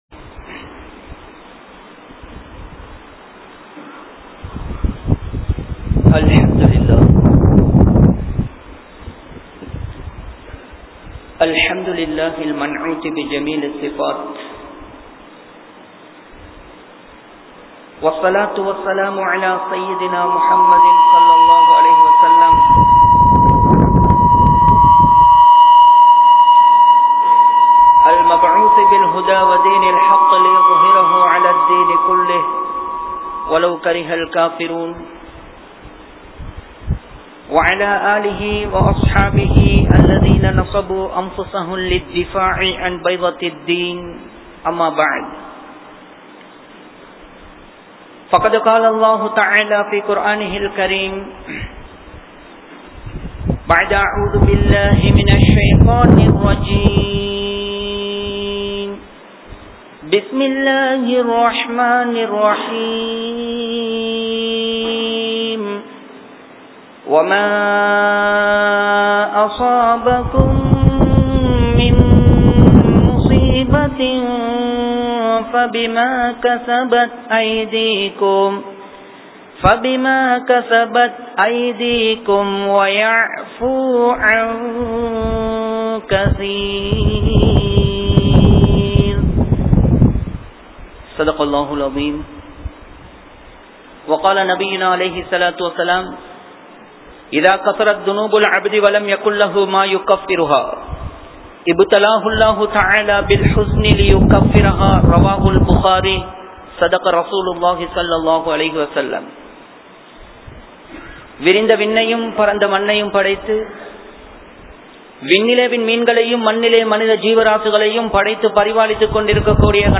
Paavaththin Vilaivuhal (பாவத்தின் விளைவுகள்) | Audio Bayans | All Ceylon Muslim Youth Community | Addalaichenai